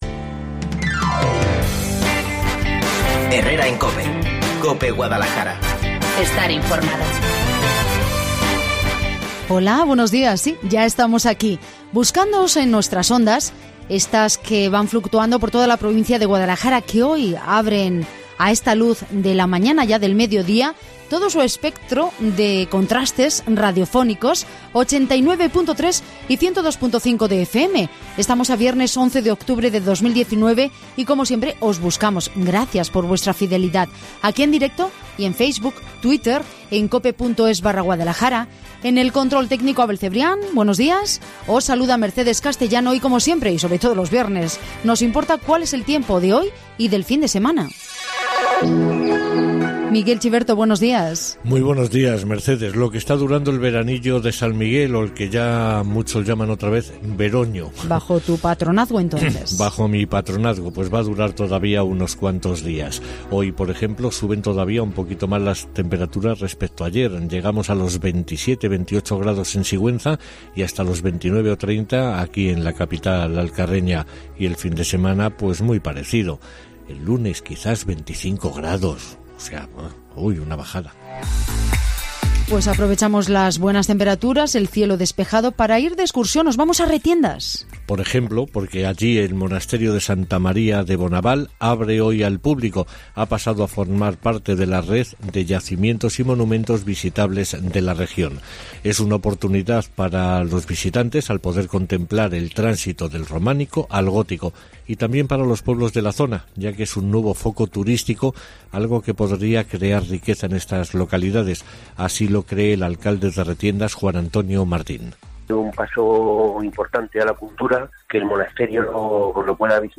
Entre 12.50 y 13:00, nos acercamos a la carpa "El farmacéutico que necesitas".